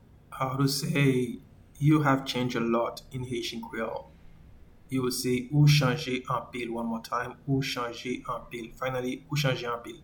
Pronunciation:
You-have-changed-a-lot-in-Haitian-Creole-Ou-chanje-anpil.mp3